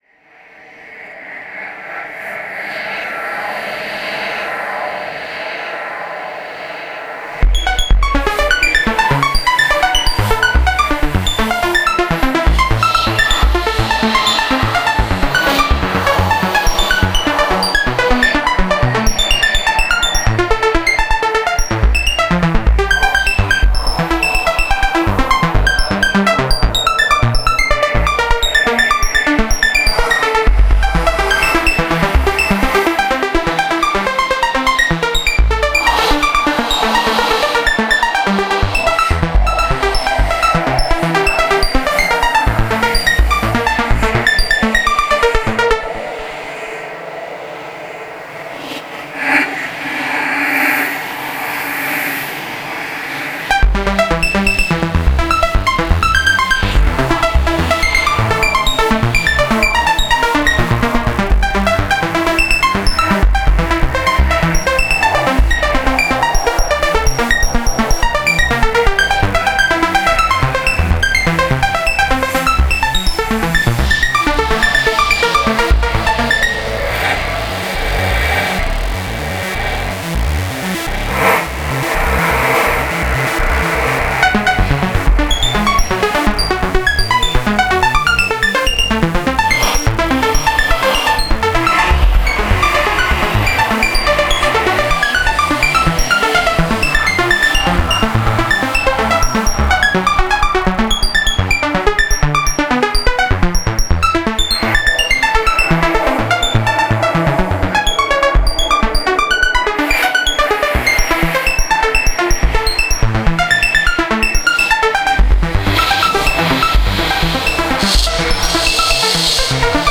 Genre: EBM, IDM.